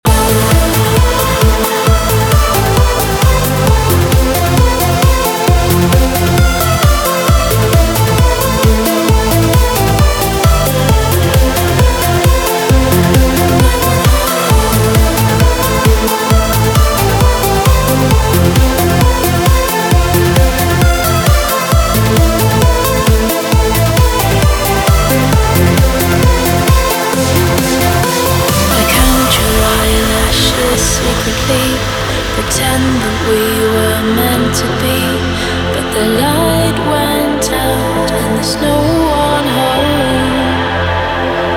громкие
EDM
красивый женский голос
progressive trance
Trance
Транс, уносящий в космос